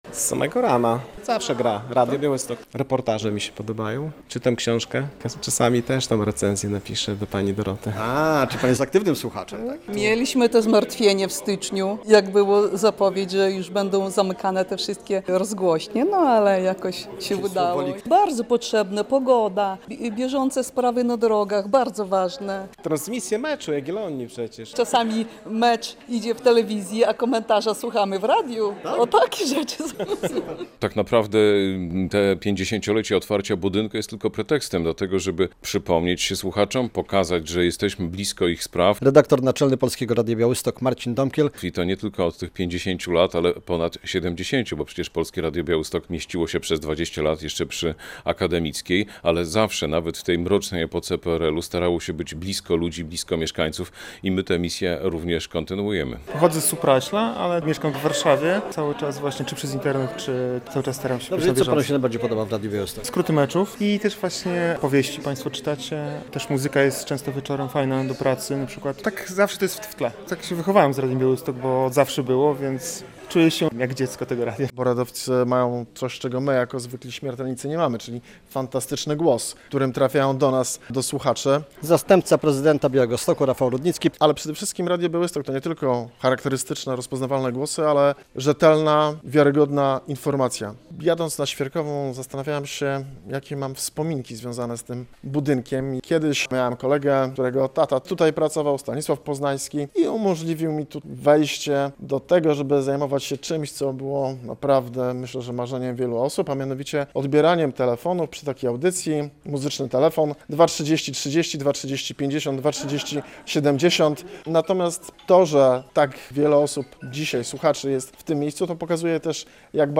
relacja
To już 50 lat, od kiedy Polskie Radio Białystok nadaje swój program z budynku przy ulicy Świerkowej 1 w Białymstoku. Z tej okazji spotkaliśmy się w piątek (26.07) z naszymi Słuchaczami, których częstowaliśmy tortem, oprowadziliśmy po rozgłośni i pokazaliśmy, jak powstaje program i jak na co dzień pracujemy.